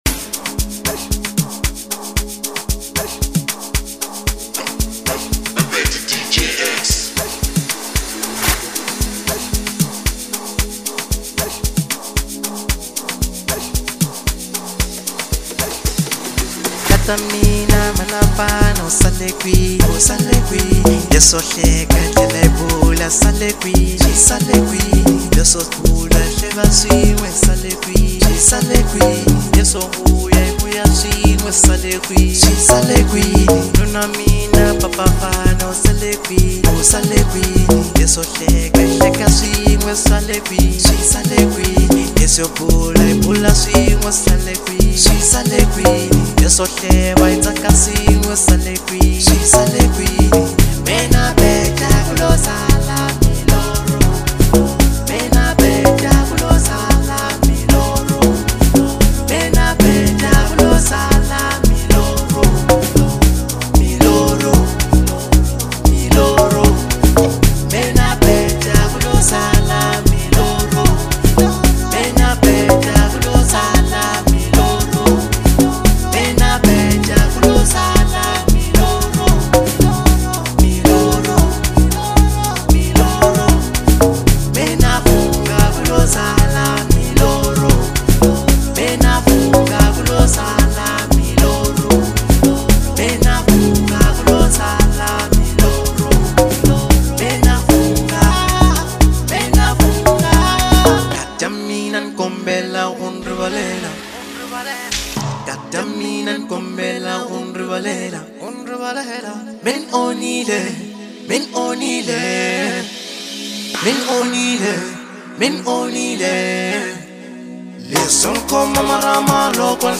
Bolo House